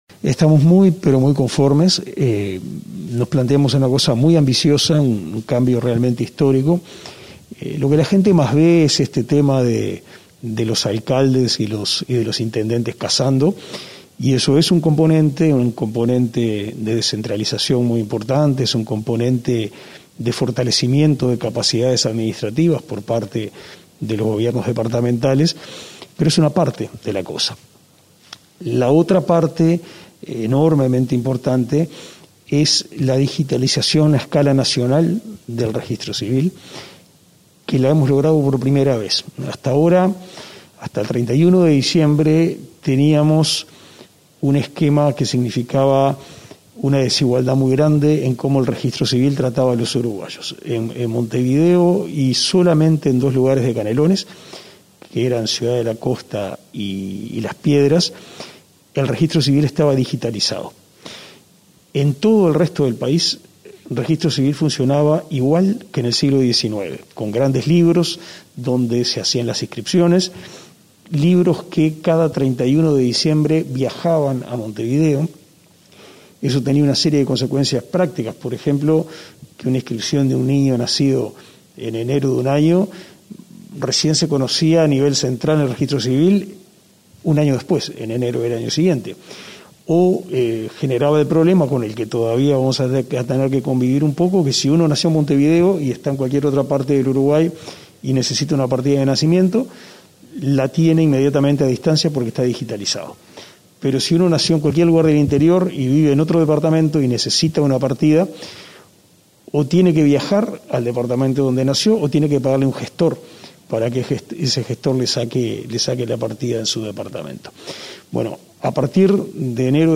Entrevista al ministro de Educación y Cultura, Pablo da Silveira